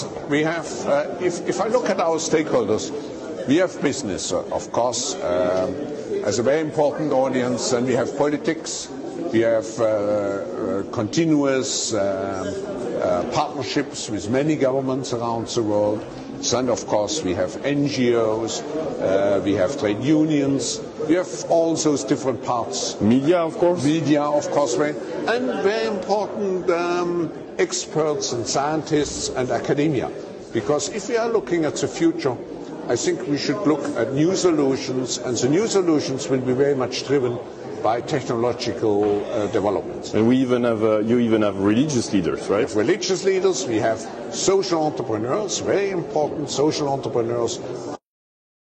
Hier erleben wir Klaus Schwab wie er so richtig schön damit prahlt, wen sie so alles unter ihrer Kontrolle haben...